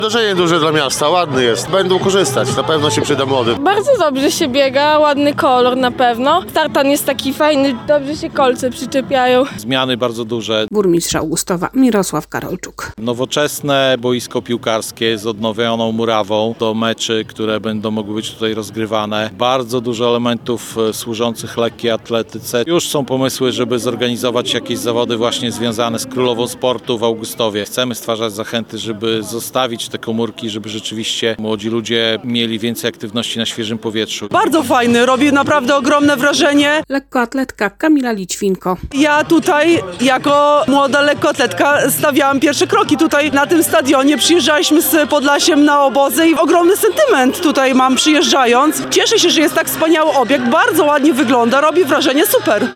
Otwarciu towarzyszył mitting lekkoatletyczny z udziałem uznanych sportowców, a wszystko obserwowały tłumy mieszkańców regionu.
Zmiany są bardzo duże - podkreśla burmistrz Augustowa Mirosław Karolczuk.
Obiekt robi ogromne wrażenie - podkreśla lekkoatletka Kamila Lićwinko.